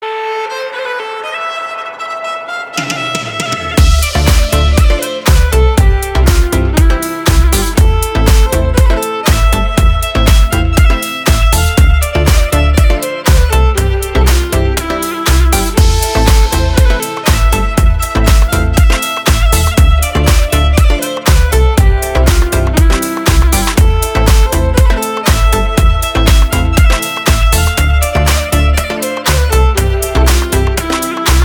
• Качество: 320, Stereo
ритмичные
deep house
без слов
скрипка
Красивый deep house со скрипкой